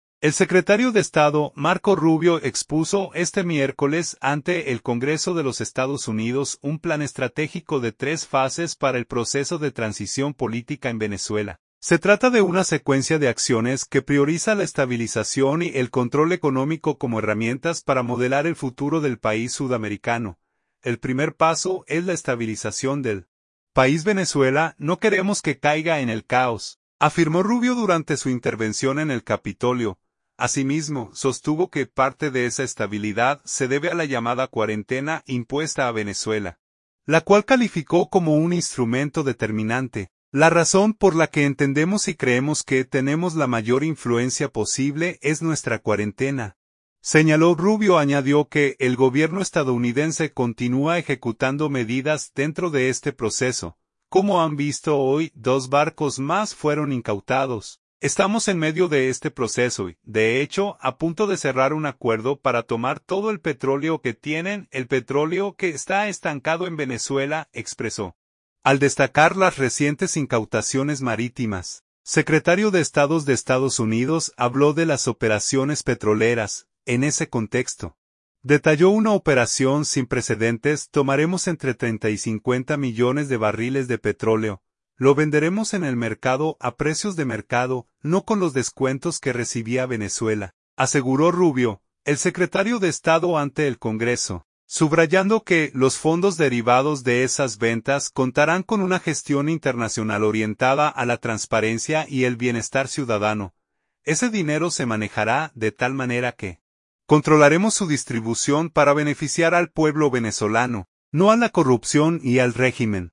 El secretario de Estado, Marco Rubio expuso este miércoles ante el Congreso de los Estados Unidos un plan estratégico de tres fases para el proceso de transición política en Venezuela.